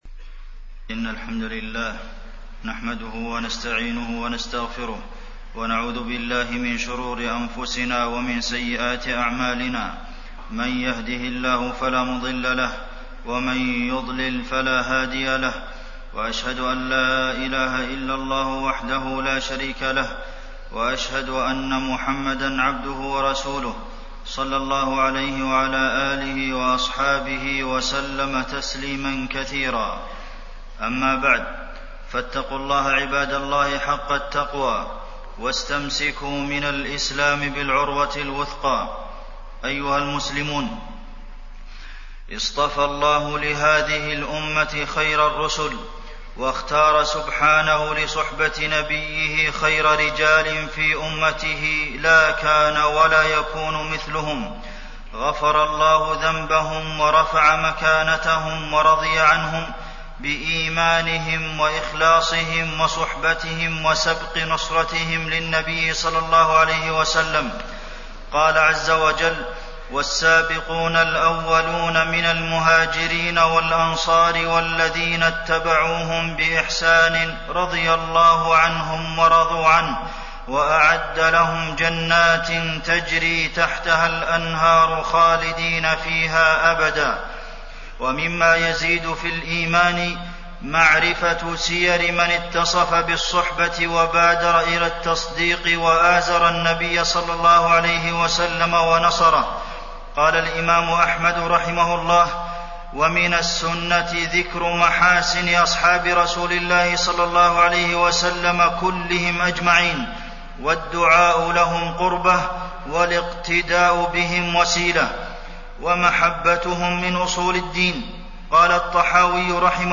تاريخ النشر ٢٢ ربيع الأول ١٤٣٢ هـ المكان: المسجد النبوي الشيخ: فضيلة الشيخ د. عبدالمحسن بن محمد القاسم فضيلة الشيخ د. عبدالمحسن بن محمد القاسم سيرة عثمان بن عفان